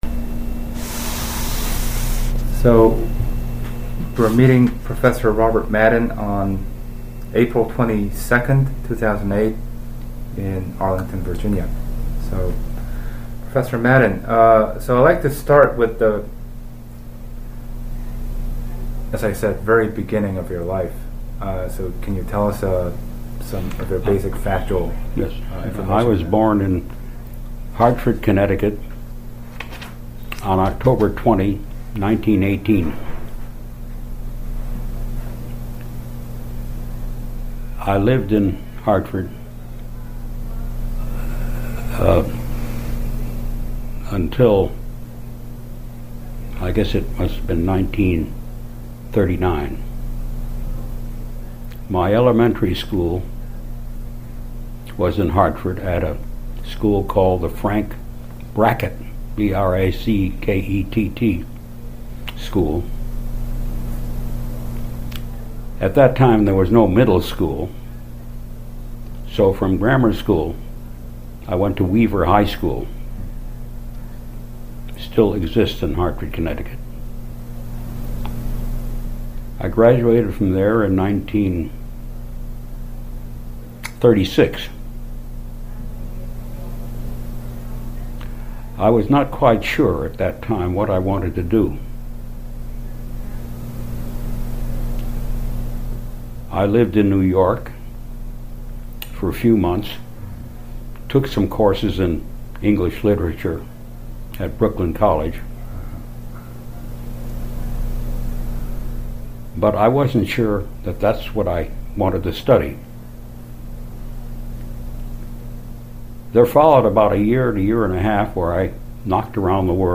Place of interview Virginia--Arlington
Genre Oral histories